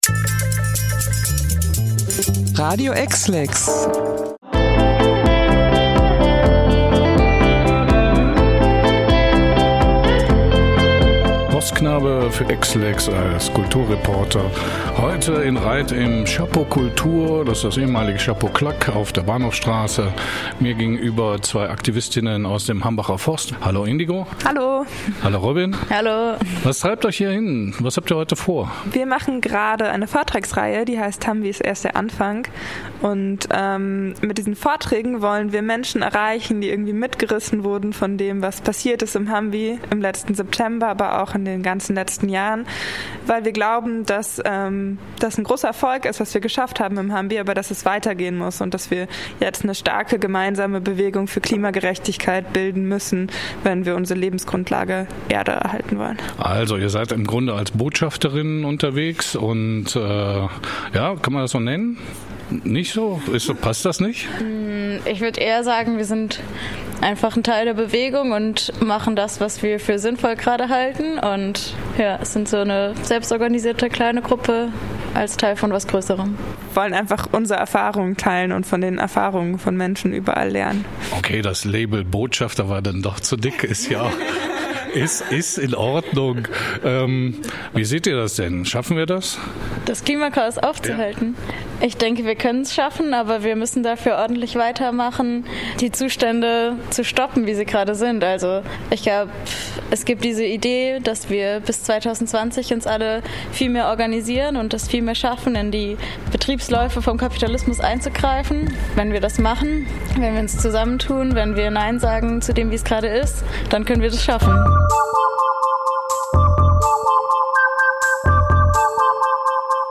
Die beiden interviewten Aktivisten sehen sich als Teil eines Größeren und glauben daran, dass das Klima-Chaos aufzuhalten ist … auch wenn es noch ein harter Kampf ist!
Interview-Hambi-bleibt-HK-TB.mp3